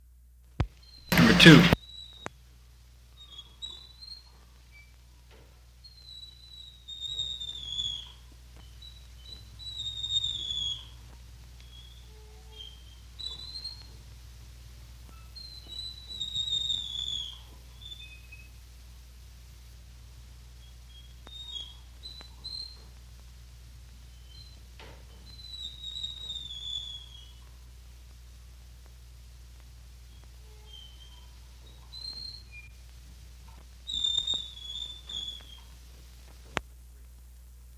古老的吱吱声 " G2712安静的木制吱吱声
描述：安静，长而低的木制吱吱声，带有宽大的上升和下降音符。
我已将它们数字化以便保存，但它们尚未恢复并且有一些噪音。